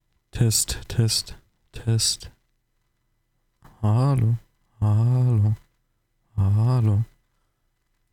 ShureSM7b Störgeräusch seit 3 Tagen
Habe das Mikrofon mit einem Bundle mit nem GoXLR etc drinnen gekauft und es lief auch circa nen Monat lang alles super nur seit 4 Tagen ist da jetzt immer so ein nerviges Geräusch und ich habe keine Ahnung wie es plötzlich aus dem nichts zustande kam und wie ich es wieder weg bekomme :/